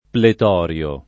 [ plet 0 r L o ]